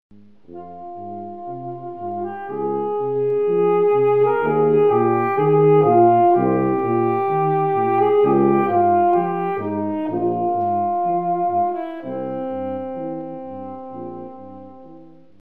02 duo cuivres .mp3